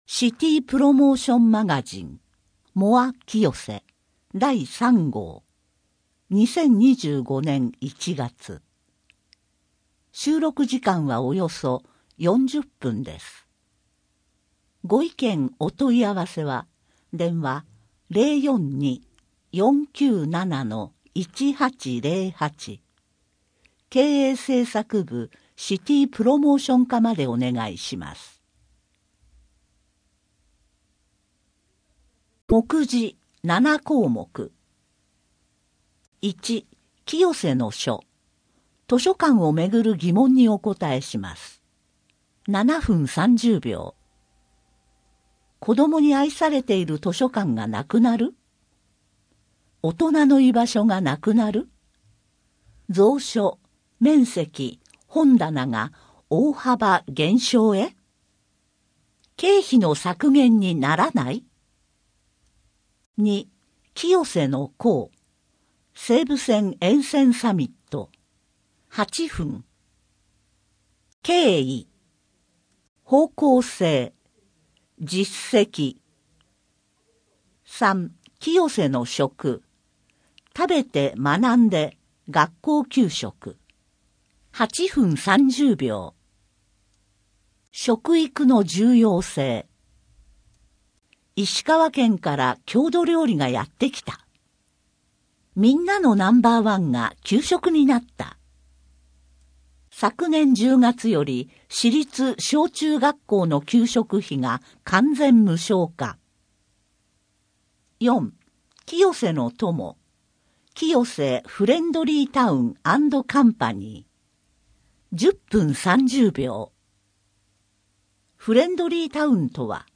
声の広報は清瀬市公共刊行物音訳機関が制作しています。